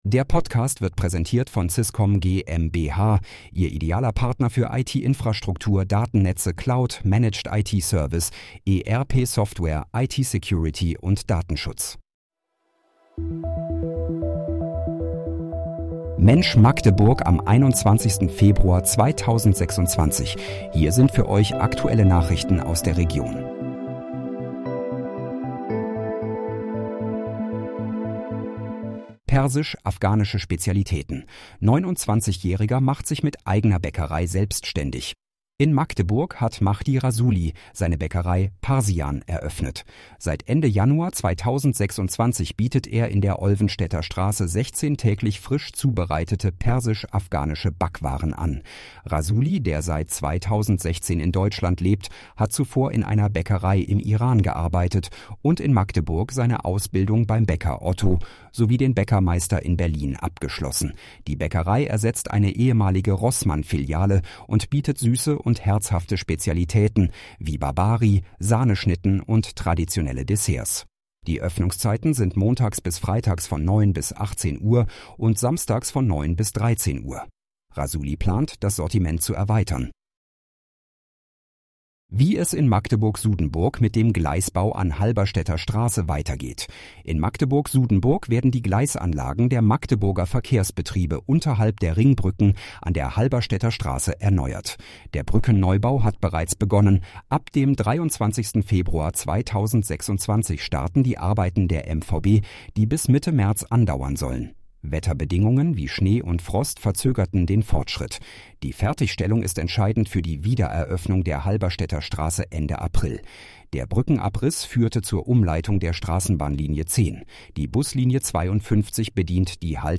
Mensch, Magdeburg: Aktuelle Nachrichten vom 21.02.2026, erstellt mit KI-Unterstützung
Nachrichten